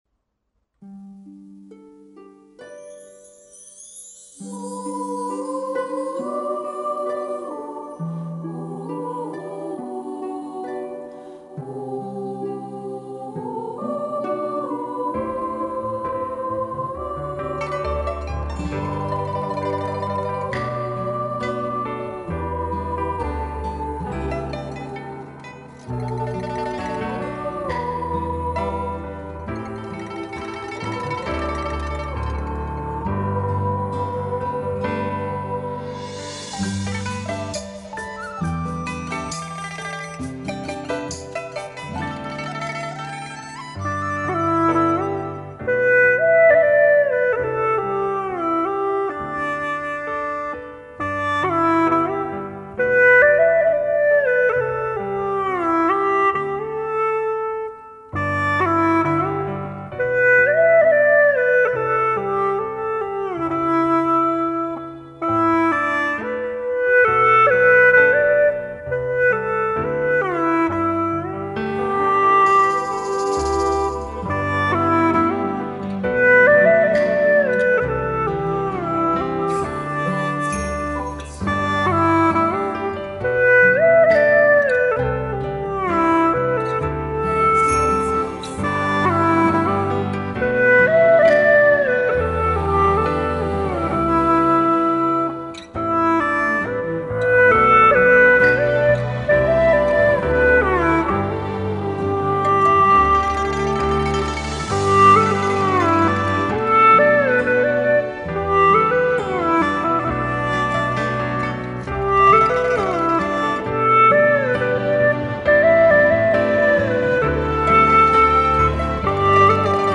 调式 : G 曲类 : 流行
强弱有度,柔柔的,衔接的真好。